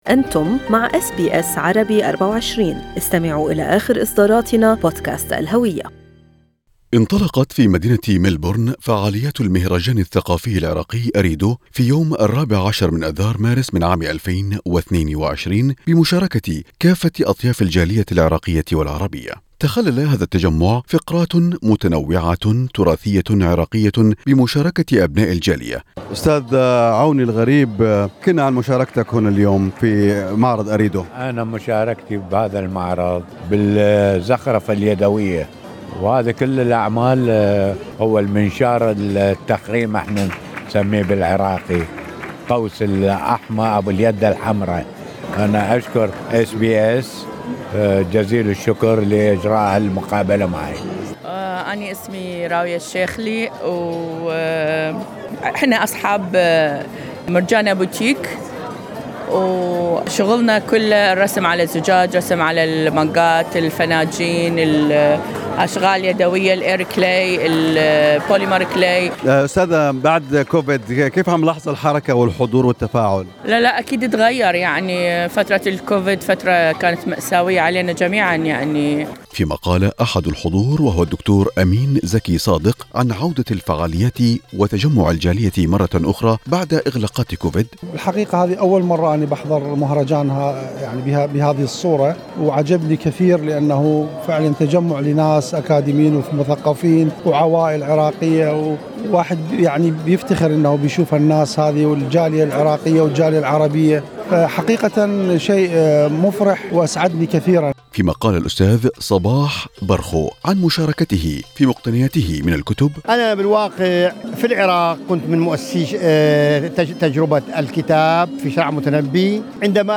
حضرت إذاعة أس بي أس عربي24 المهرجان الثقافي العراقي-أريدو وشاهدت تفاعل الحاضرين الذين تدفقوا إلى قاعة المهرجان.